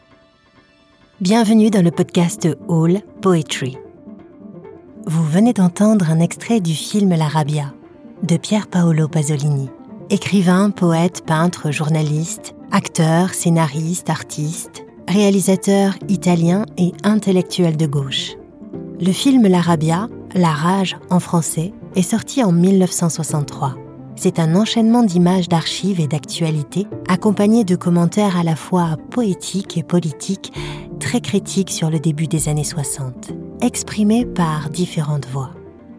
French voice over actress native from France, neutral accent.
My voice can be natural, sensual, dramatic, playful, friendly, institutional, warm and much more …
Mezzo-Soprano